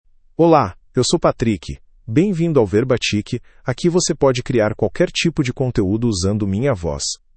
Patrick — Male Portuguese (Brazil) AI Voice | TTS, Voice Cloning & Video | Verbatik AI
MalePortuguese (Brazil)
Patrick is a male AI voice for Portuguese (Brazil).
Voice sample
Male
Patrick delivers clear pronunciation with authentic Brazil Portuguese intonation, making your content sound professionally produced.